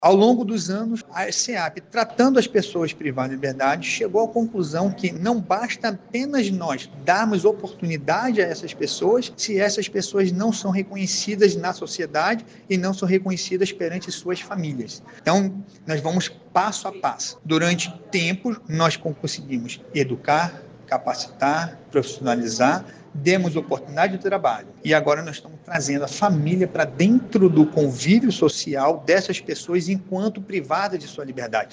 Sonora-1-Paulo-Cesar.mp3